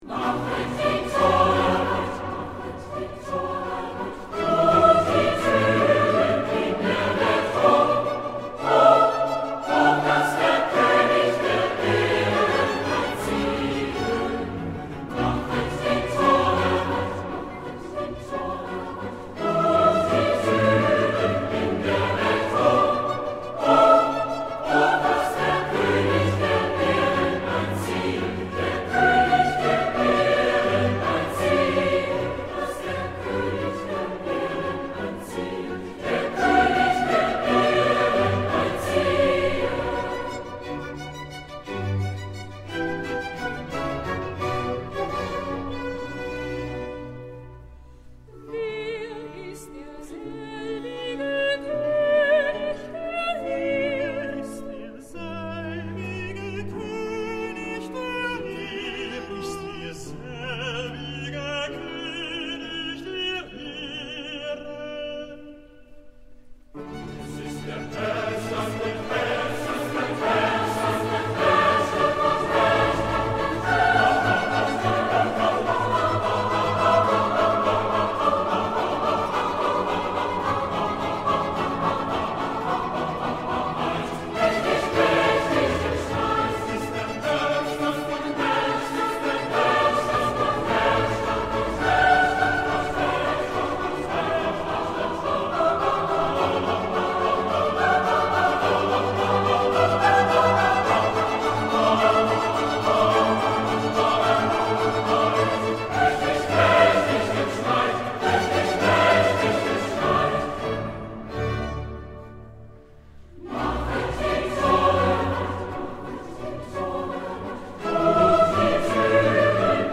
Stimmen - MIDI / mp3 (Chor) mp3